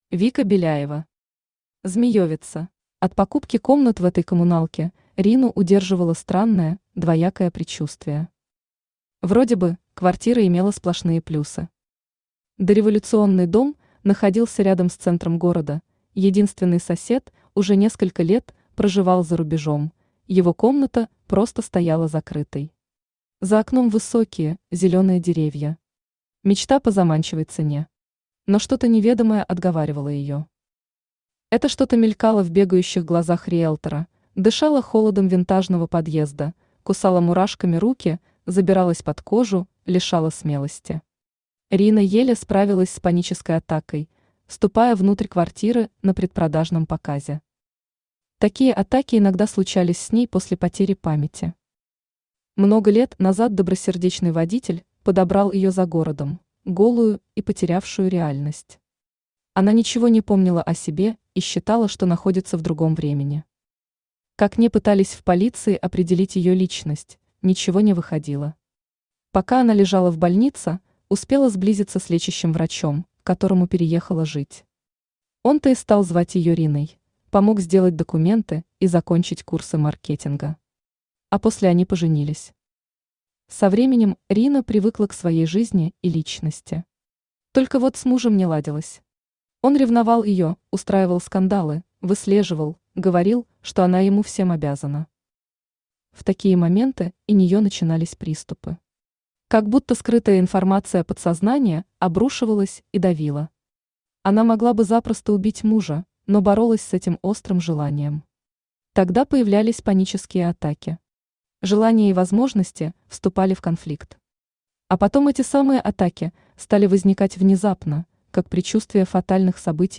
Аудиокнига Змеевица | Библиотека аудиокниг
Aудиокнига Змеевица Автор Вика Беляева Читает аудиокнигу Авточтец ЛитРес.